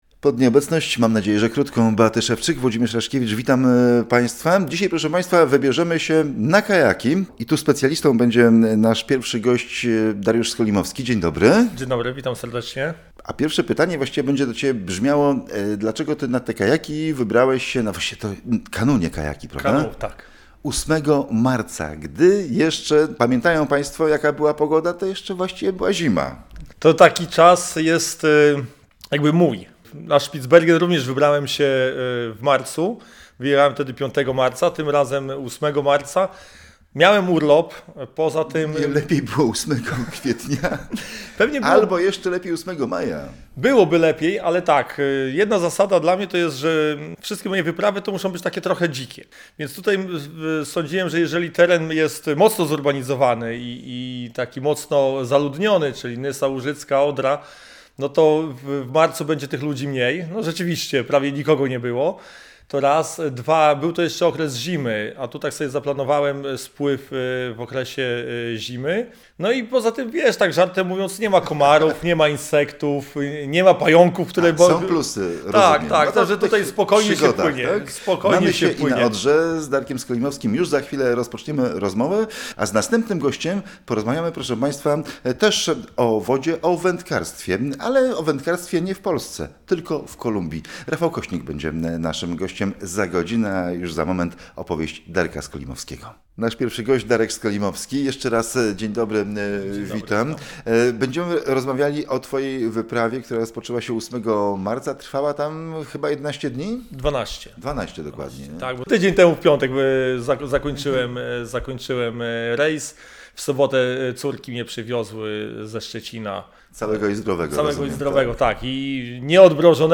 Tymczasem w ostatniej audycji „Zwiedzaj z Radiem Gdańsk” spotkaliśmy się z dwójką mieszkańców Pomorza, którzy postanowili, pomimo pandemii, spełnić swoje podróżnicze marzenia.